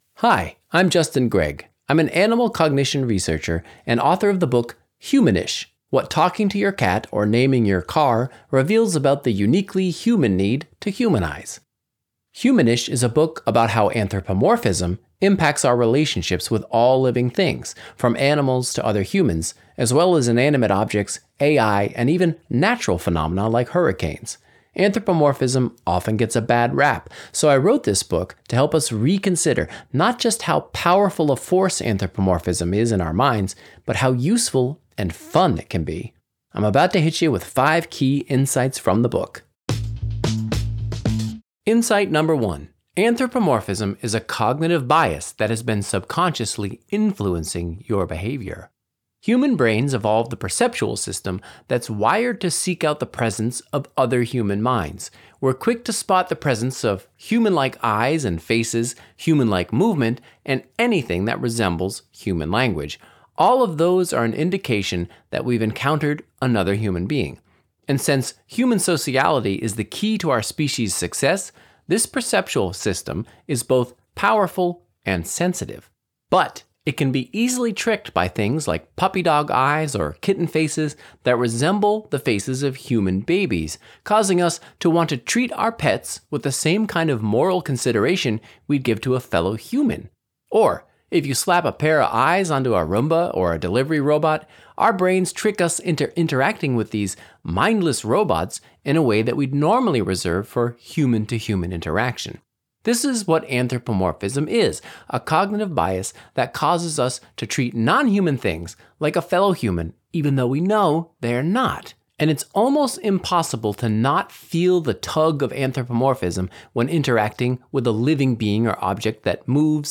Enjoy our full library of Book Bites—read by the authors!—in the Next Big Idea App: